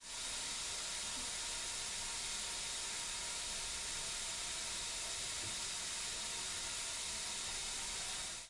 自来水
描述：在水槽里运行自来水
Tag: 水槽 水龙头 运行